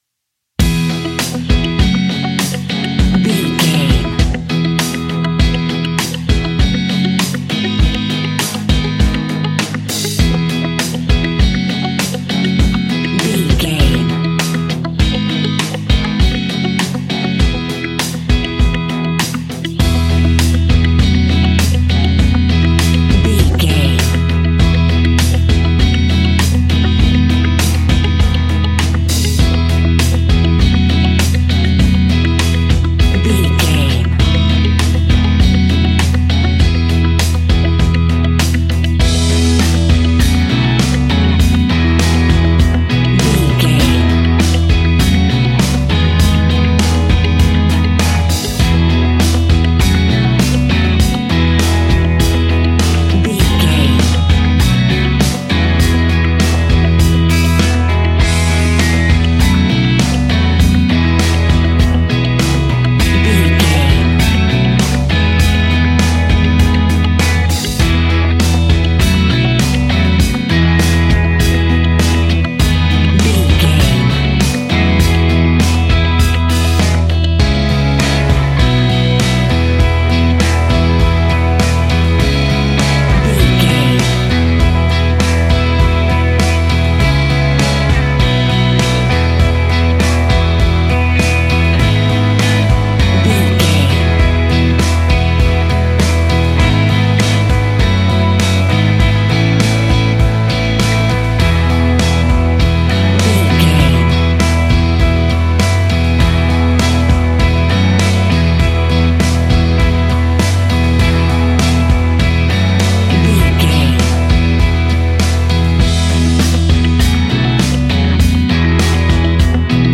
Ionian/Major
indie pop
energetic
uplifting
instrumentals
upbeat
groovy
guitars
bass
drums
piano
organ